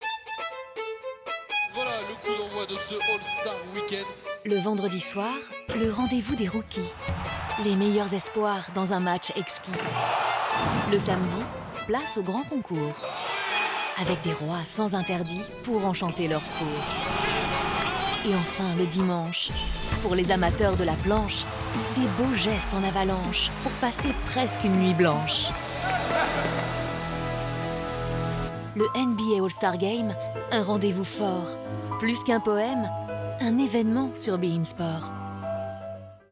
Voix off de la bande annonce « All Star Game » diffusée sur BeIn Sport, dans un style « Fable de La Fontaine »…